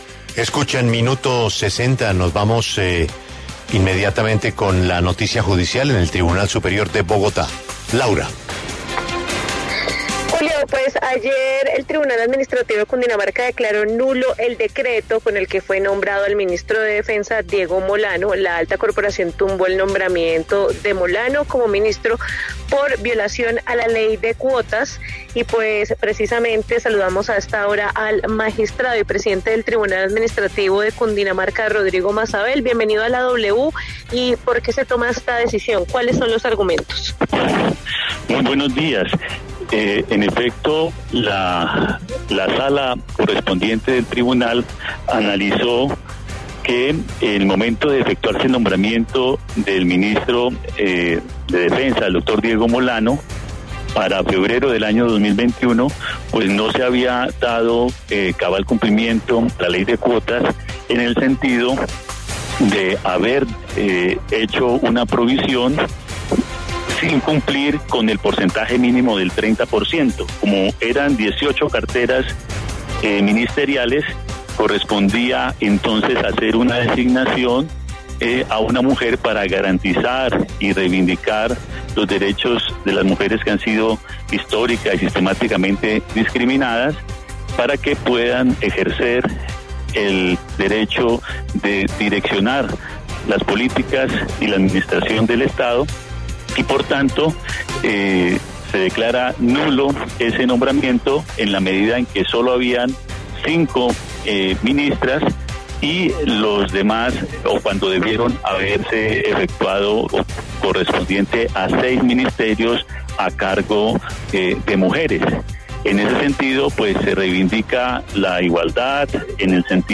En diálogo con La W, el magistrado Rodrigo Mazabel se refirió a la sentencia que señala que el presidente Iván Duque está obligado a nombrar mujeres en al menos en el 30% de los cargos de máximo nivel decisorio en los Ministerios.